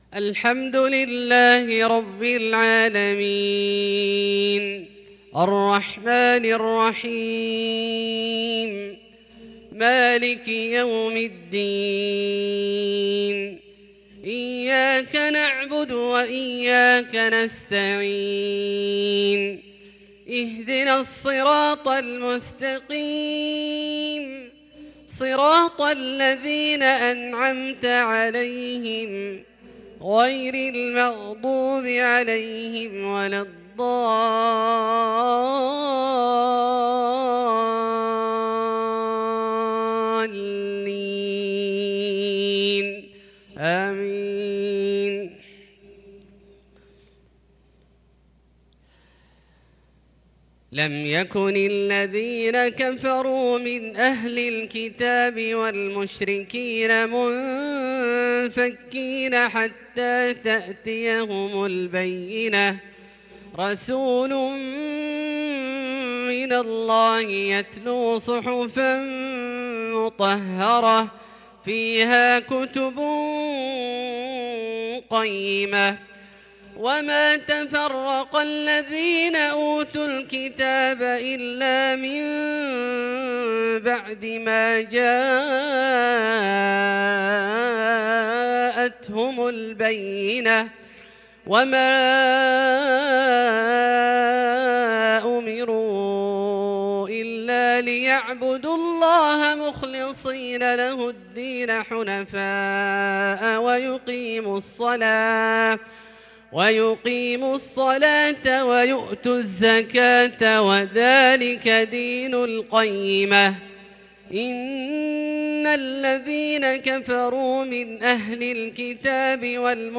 مغرب 7-6-1442هـ | سورتي البينة والتكاثر > ١٤٤٢ هـ > الفروض - تلاوات عبدالله الجهني